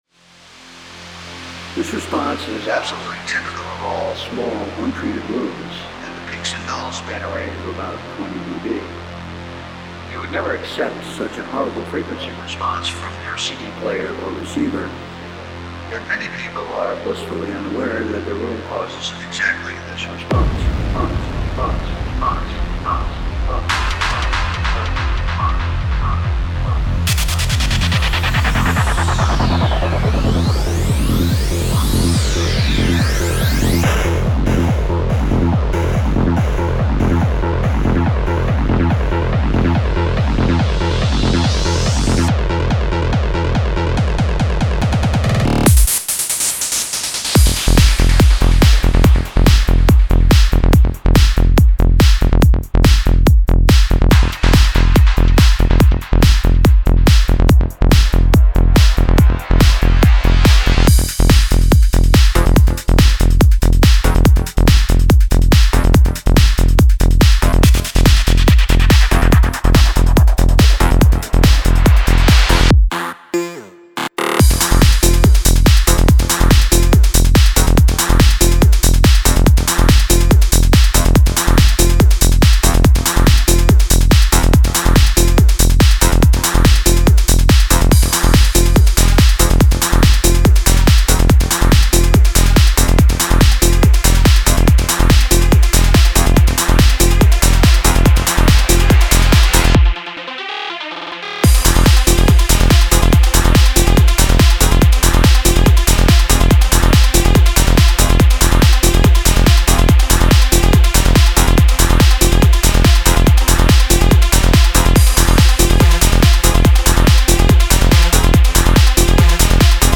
Файл в обменнике2 Myзыкa->Psy-trance, Full-on
Style: FullOn
Quality: CBRkbps / 44.1kHz / Joint-Stereo